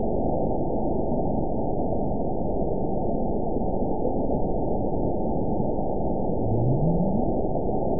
event 922155 date 12/27/24 time 18:59:48 GMT (11 months, 1 week ago) score 9.54 location TSS-AB04 detected by nrw target species NRW annotations +NRW Spectrogram: Frequency (kHz) vs. Time (s) audio not available .wav